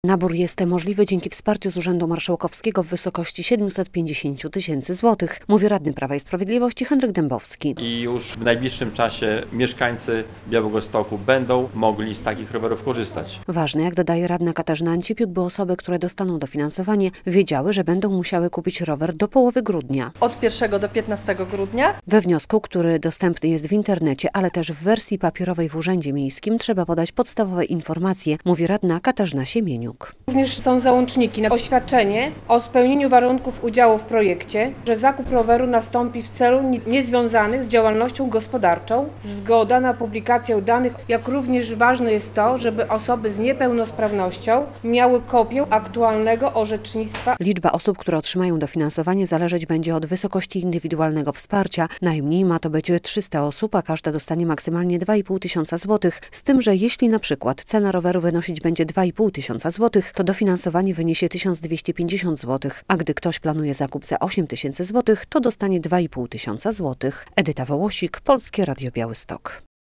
relacja
I już niedługo mieszkańcy Białegostoku będą mogli korzystać z rowerów elektrycznych - mówi radny Prawa i Sprawiedliwości Henryk Dębowski.